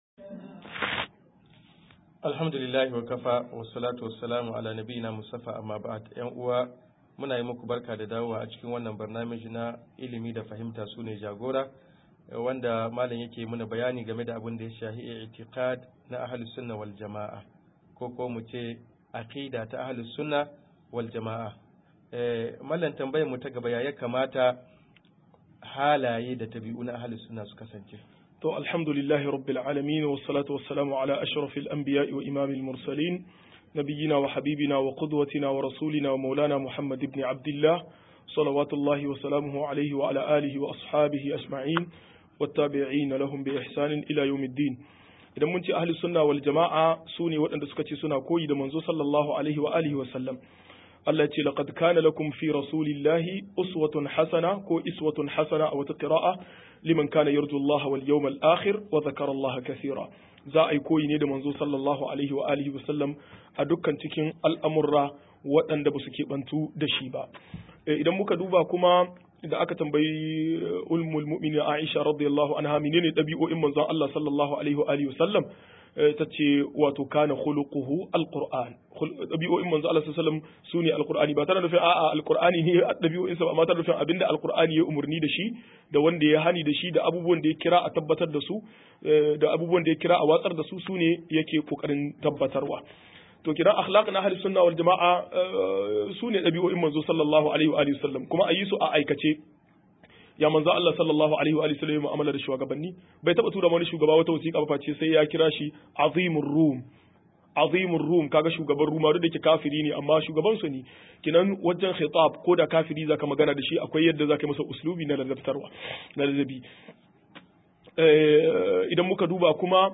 148-Bayani kan Hana aikin Haji - MUHADARA